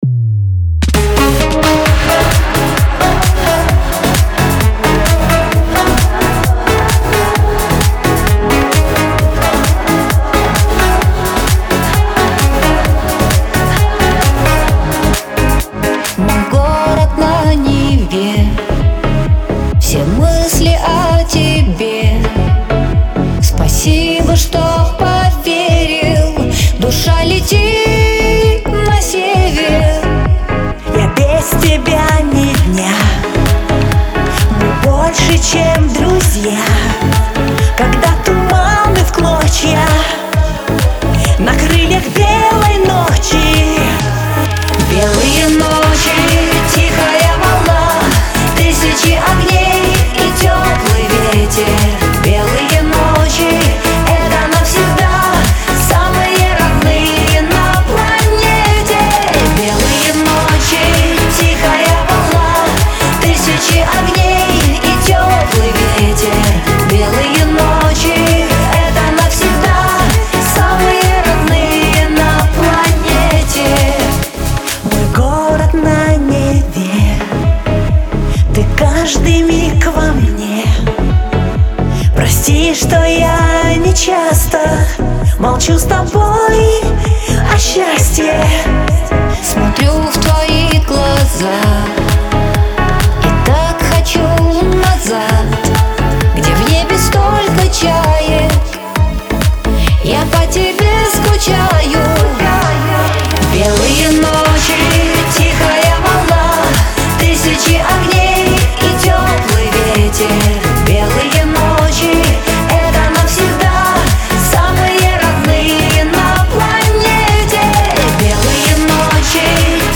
дуэт , pop
эстрада